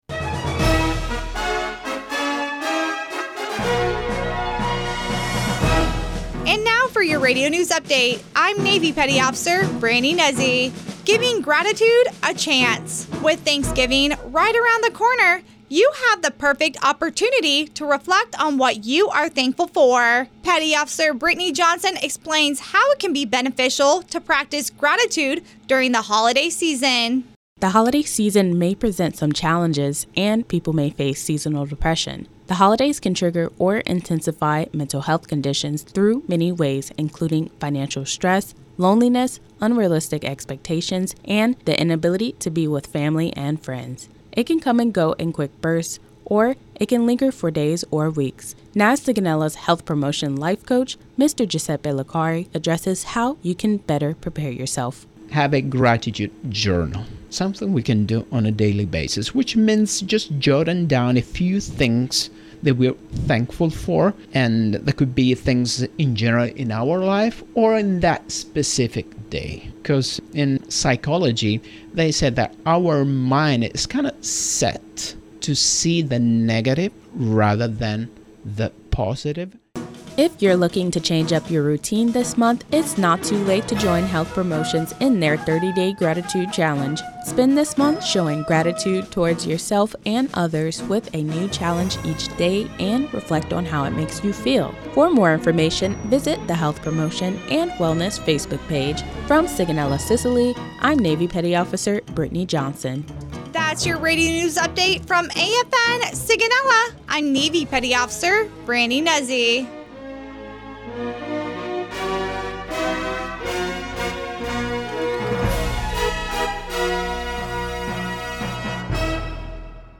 NAVAL AIR STATION SIGONELLA, Italy (November 15, 2024) Radio news highlights how to practice gratitude during the holiday season.